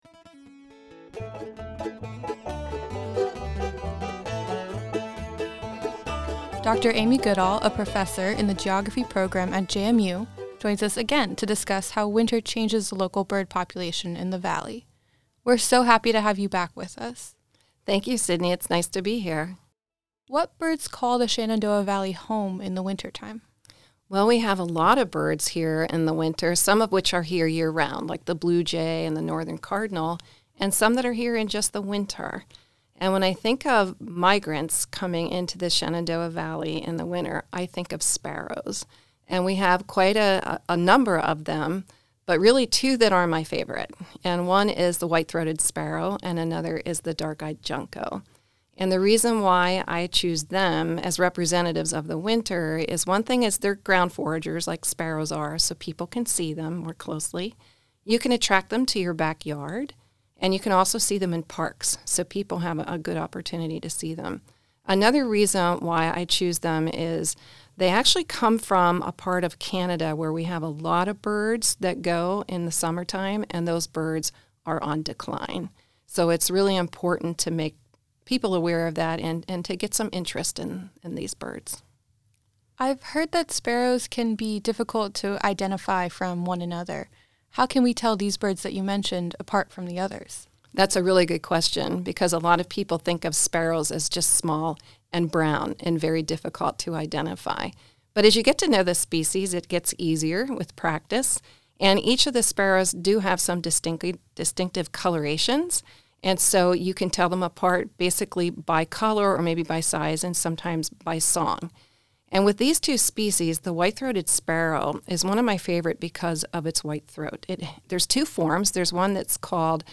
SVEG-winter-birds-interview.mp3